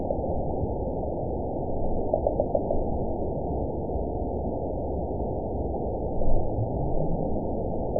event 920259 date 03/09/24 time 23:21:25 GMT (1 year, 2 months ago) score 5.02 location TSS-AB02 detected by nrw target species NRW annotations +NRW Spectrogram: Frequency (kHz) vs. Time (s) audio not available .wav